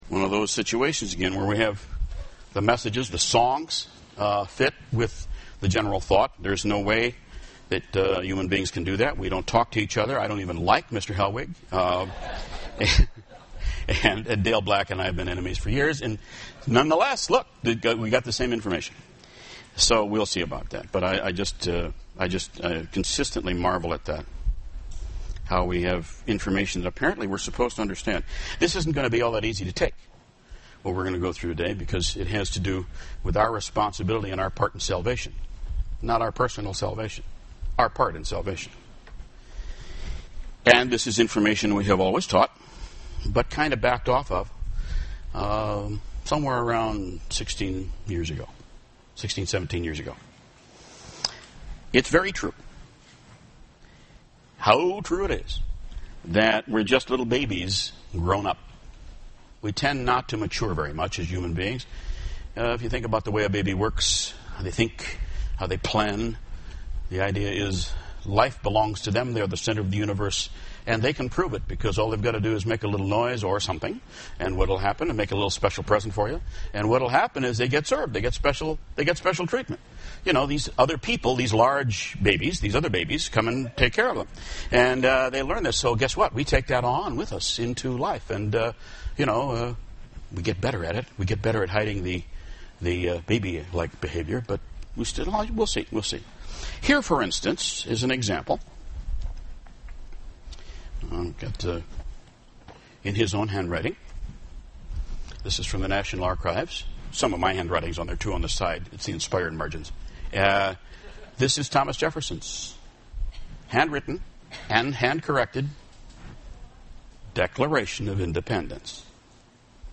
UCG Sermon Notes Intro: How true it is – we are just little babies grown up!